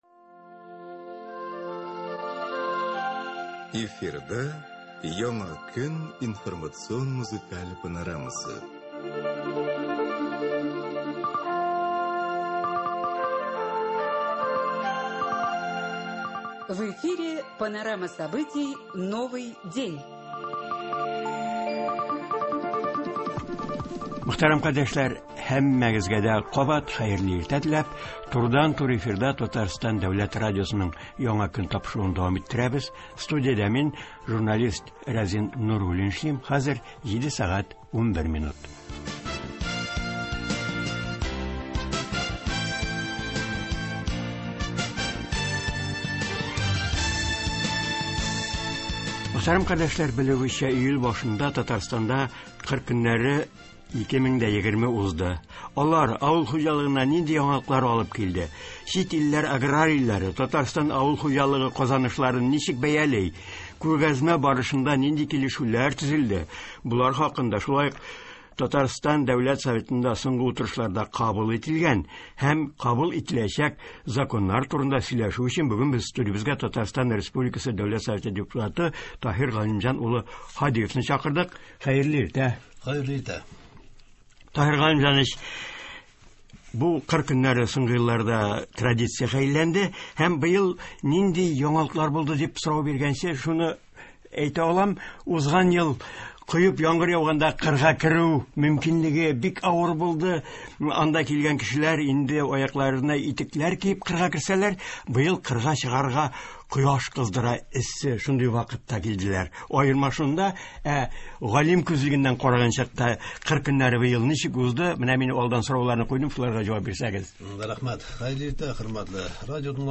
Туры эфир. 13 июль.
“Татарстанда кыр көне-2020” авыл хуҗалыгына нинди яңалыклар алып килде? Чит илләр аграрийлары Татарстан авыл хуҗалыгы казанышларын ничек бәяли? Күргәзмә барышында нинди килешүләр төзелде? Болар хакында, шулай ук Татарстан Дәүләт Советында соңгы утырышларда кабул ителгән законнар турында турыдан-туры эфирда Татарстан республикасы Дәүләт Советы депутаты Таһир Һадиев сөйли һәм тыңлаучылар сорауларына җавап бирә.